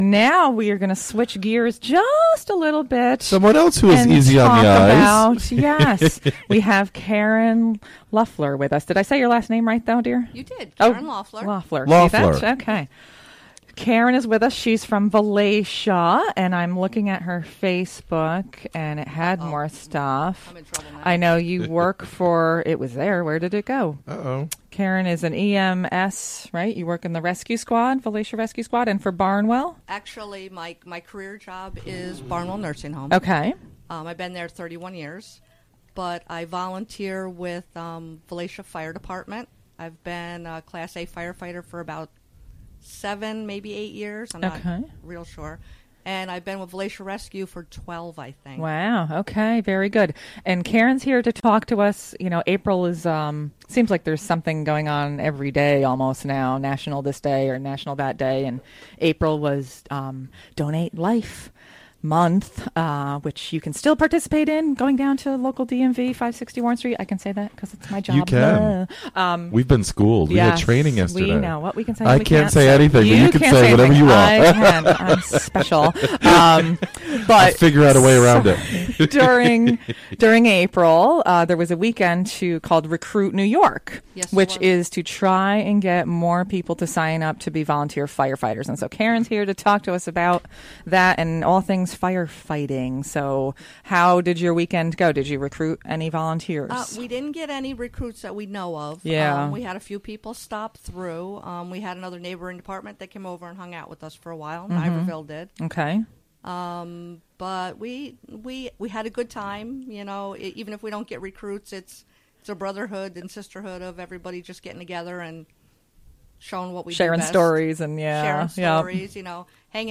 11am The show features interviews and discussion with p...